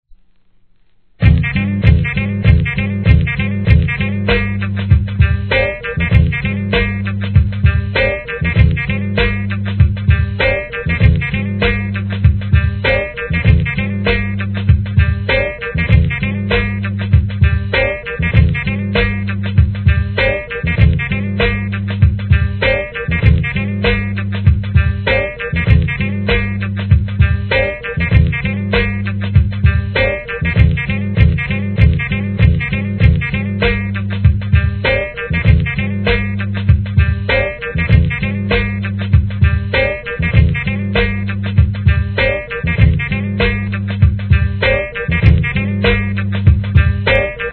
HIP HOP/R&B
FUNKYブレイク・ビーツ集!!